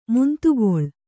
♪ muntugoḷ